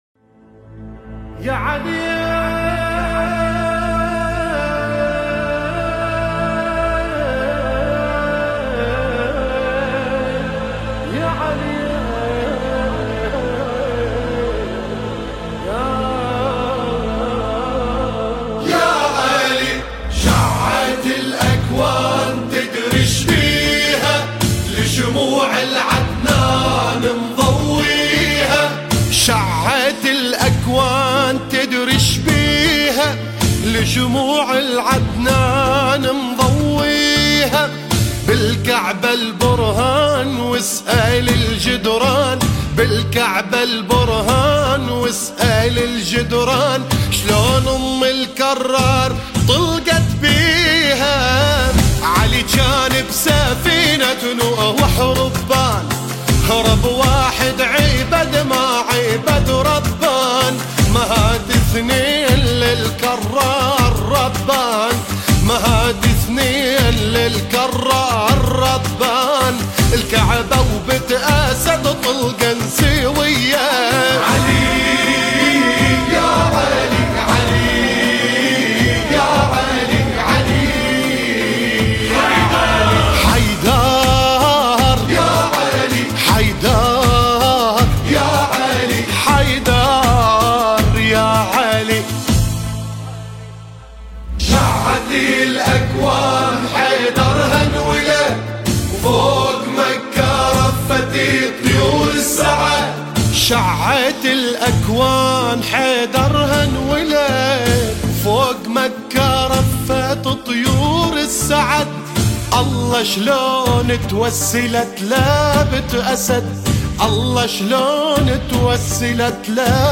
الابوذيات:مجموعة من شعراء وخدام الامام الحسين من العراق